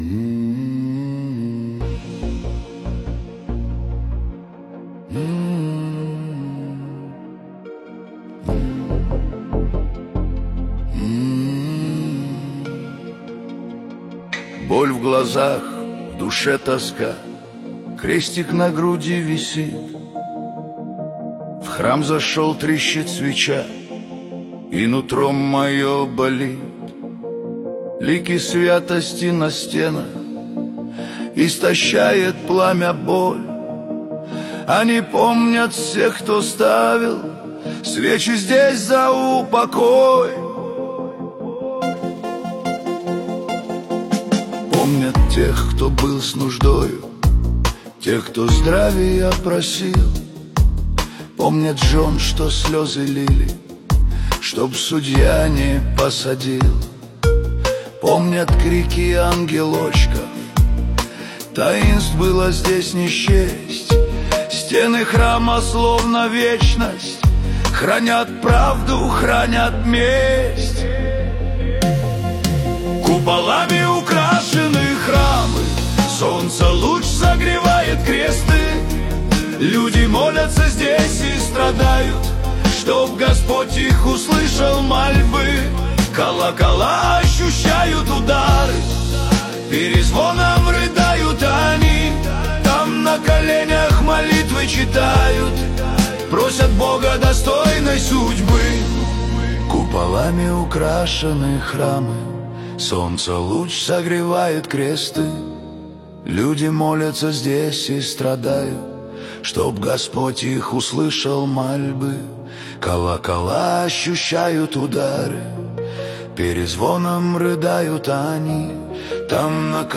Качество: 320 kbps, stereo
Поп музыка, Нейросеть Песни 2025